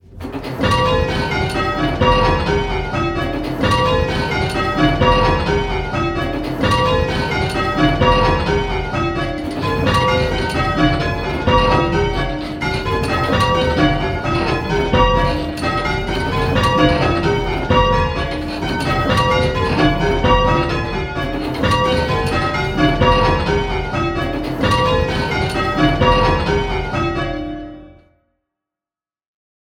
Try and pick out the treble and hear the different speeds it rings at.
4. Nearly balanced...
The other bells are only a little quieter in this version… but your ears should now be getting accustomed to what you are listening for, so you should still be able to pick out the Treble.